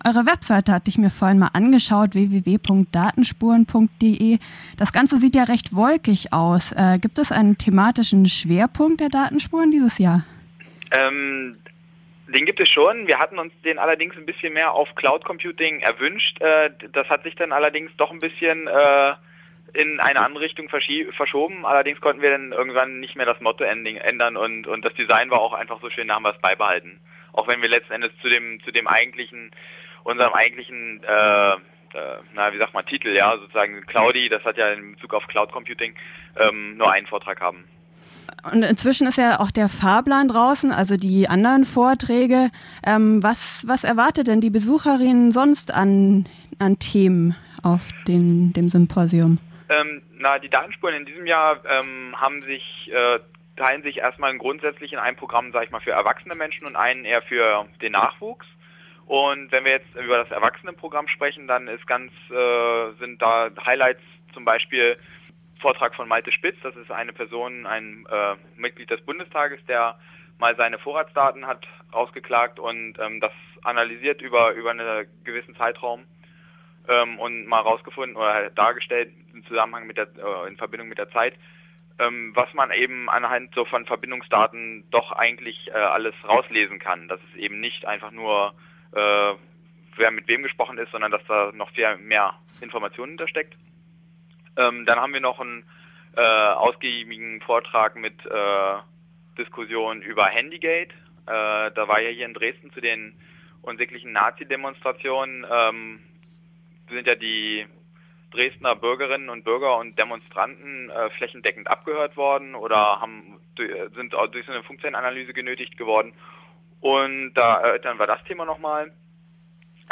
Das Symposium des Dresdner Chaos Computer clubs am 15. & 16. Oktober 2011 in Dresden zu den Spuren im Internet. Im Interview